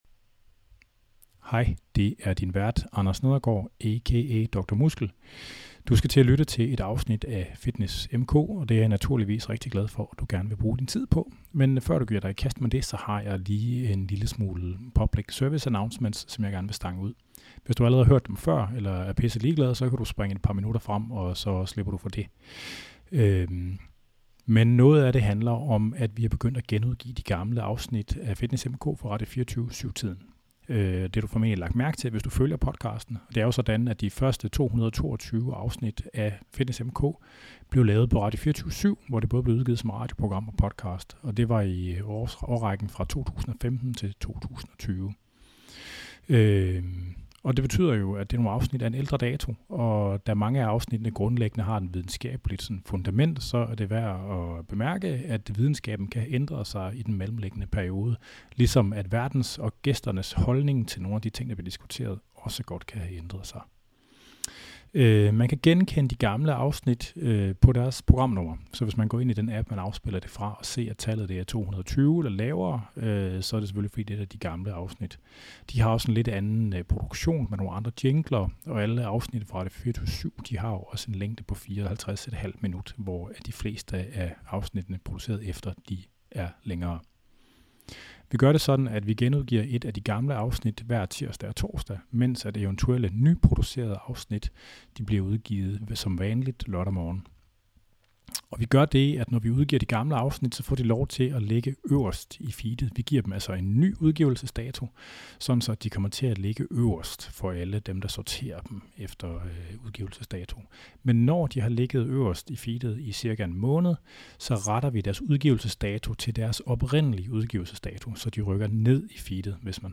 Dette fænomen kaldes transcranial direct current stimulation (TDCS) og er en speciel type af det overordnede fænomen, hvor man stimulerer dele af hjernen med elektricitet eller magnetfelter. Vi taler om hvad det her værktøj kan og ikke kan og prøver det endda i real time i studiet.